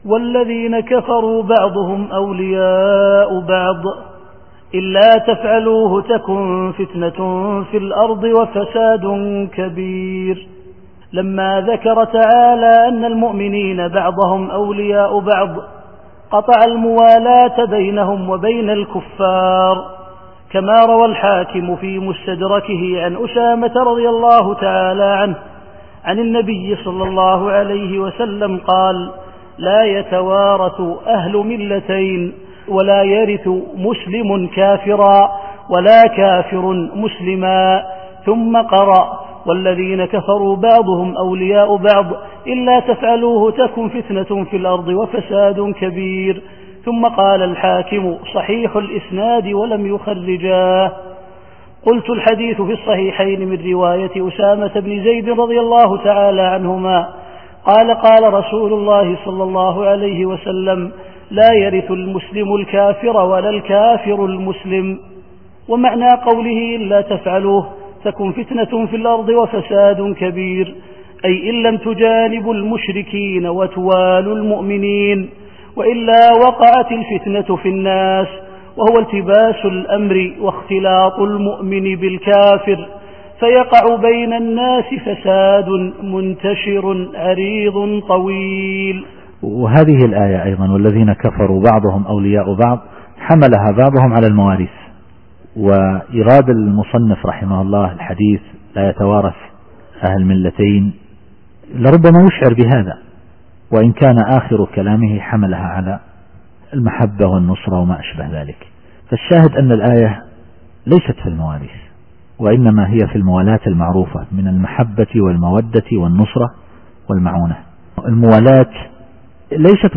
التفسير الصوتي [الأنفال / 73]